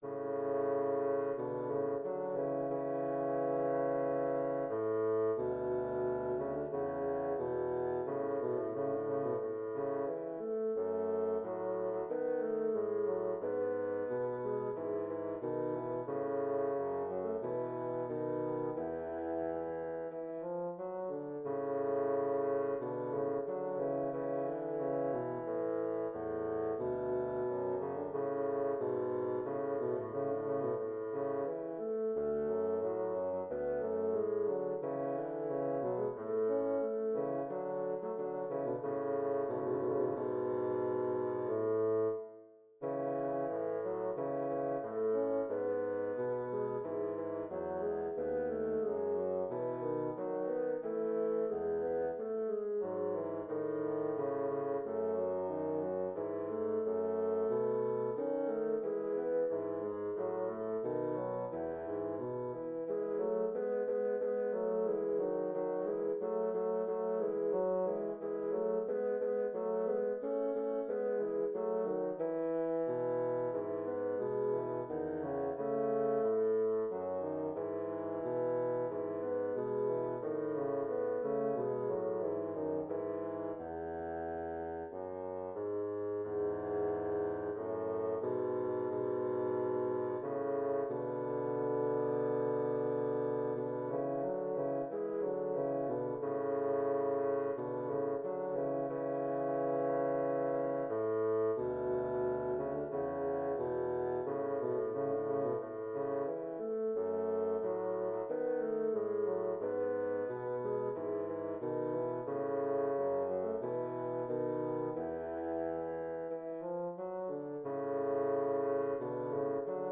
Intermediate bassoon duet
bassoon music , double reed